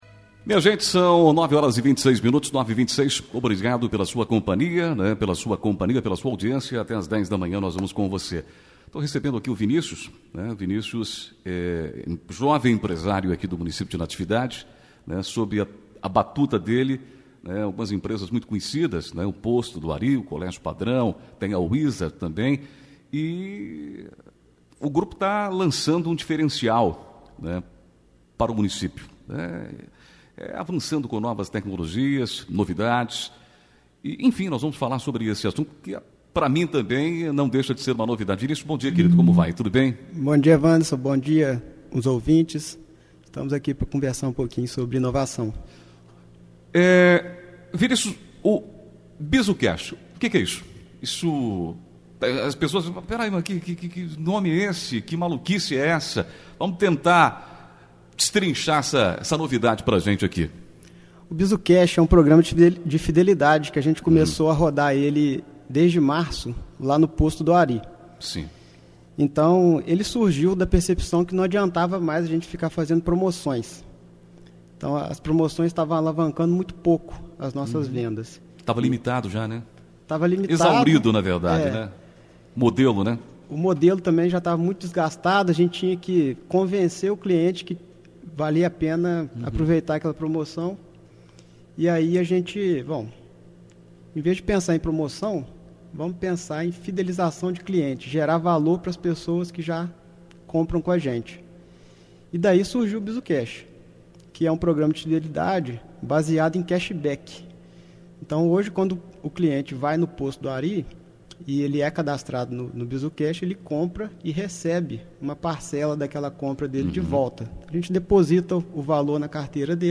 Entrevista: Empresário de Natividade aposta na inovação, devolvendo parte do dinheiro dos clientes – OUÇA – Natividade FM On-Line
A entrevista na Rádio Natividade FM marcou a abertura da segunda fase do projeto, que visa a ampliação de parcerias com outros empresários e prestação de serviços.